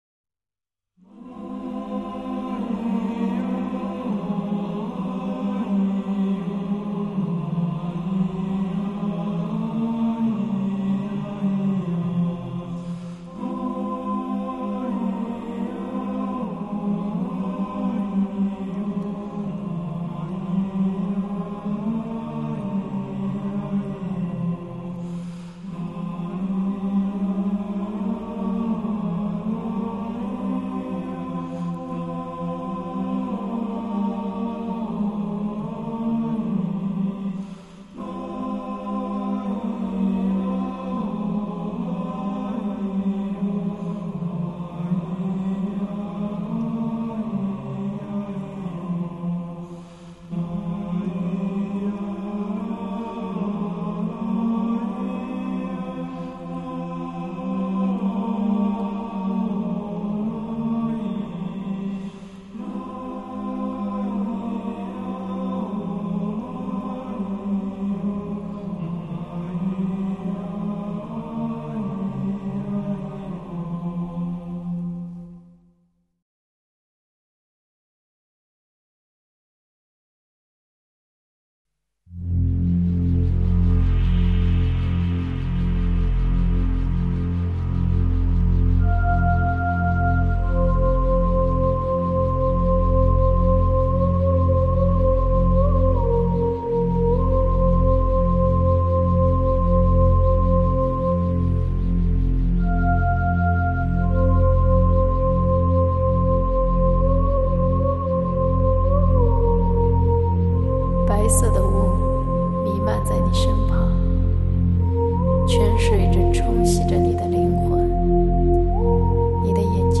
音乐风格: 世界
是一名内地女歌手，她是一个比较另类的流行歌手。